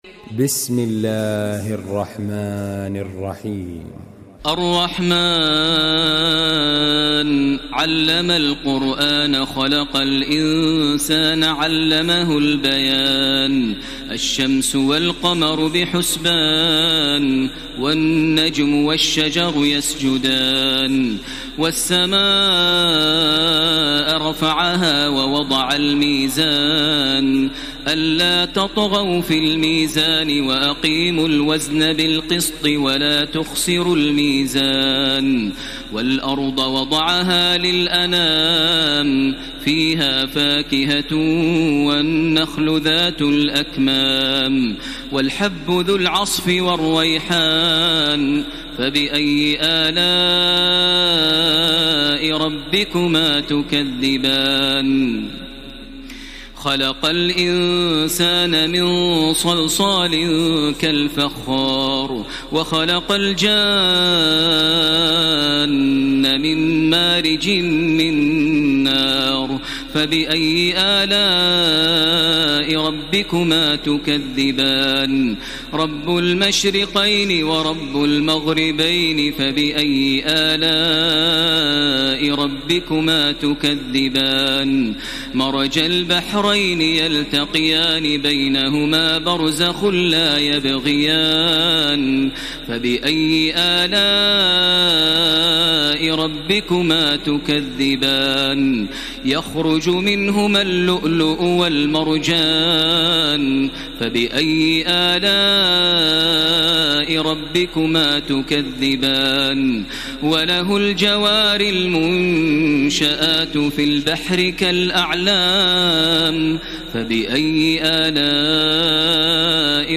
تراويح ليلة 26 رمضان 1433هـ من سور الرحمن الواقعة و الحديد Taraweeh 26 st night Ramadan 1433H from Surah Ar-Rahmaan and Al-Waaqia and Al-Hadid > تراويح الحرم المكي عام 1433 🕋 > التراويح - تلاوات الحرمين